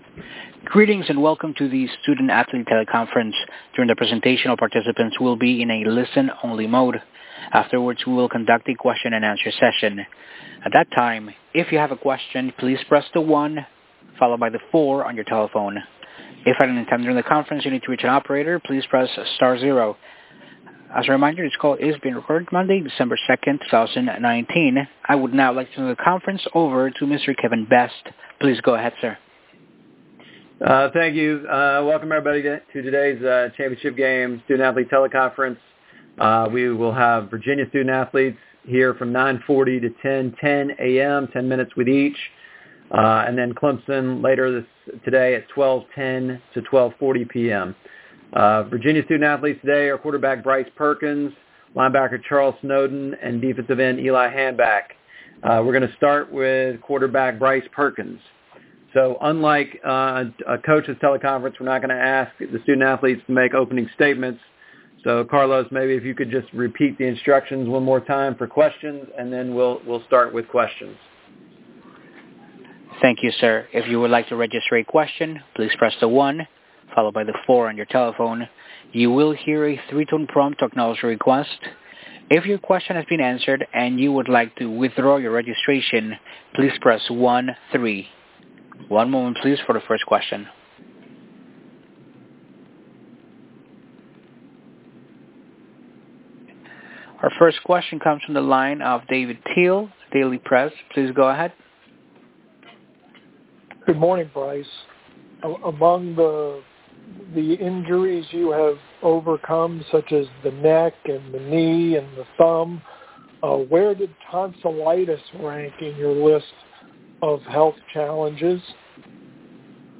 2019 ACC FCG S-A Teleconference (Virginia)